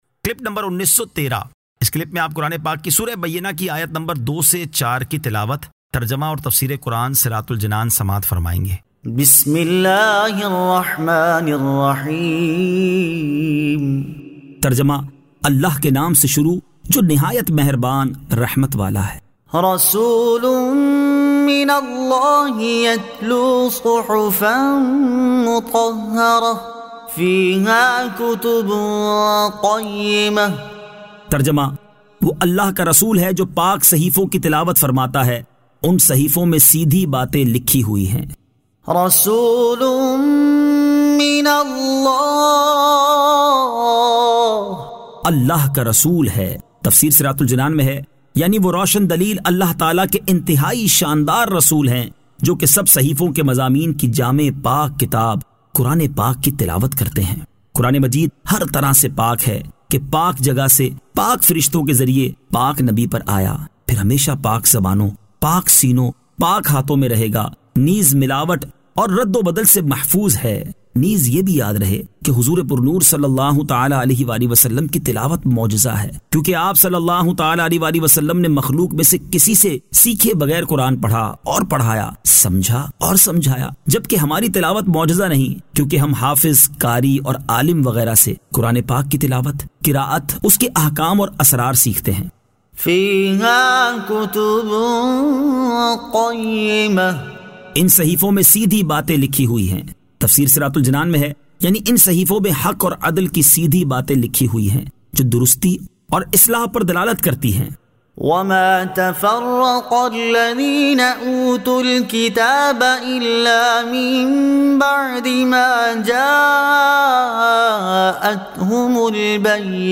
Surah Al-Bayyinah 02 To 04 Tilawat , Tarjama , Tafseer